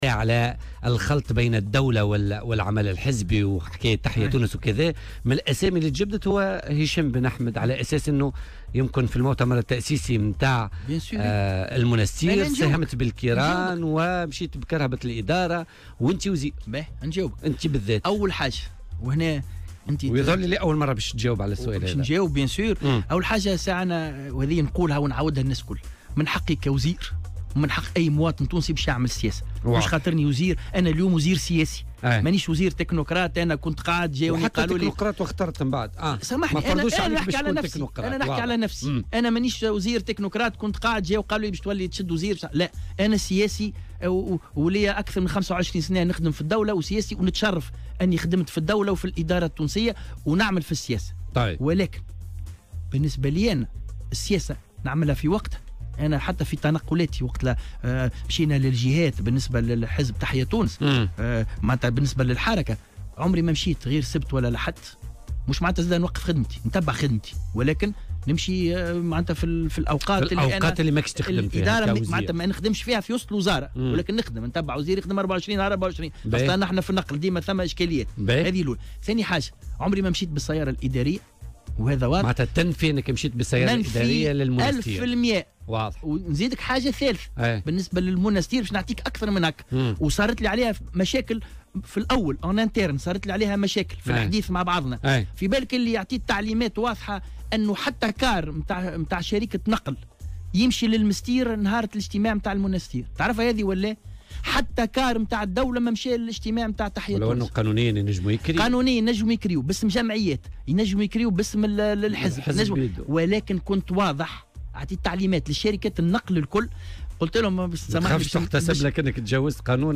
وأكد أيضا في مداخلة له اليوم في برنامج "بوليتيكا" أنه لم يستعمل السيارة الإدارية في تنقلاته الحزبية، مشيرا إلى أنه أعطى تعليمات بعدم كراء حافلات خلال الاجتماع التأسيسي لحركة "تحيا تونس" على الرغم من أن القانون يسمح بذلك.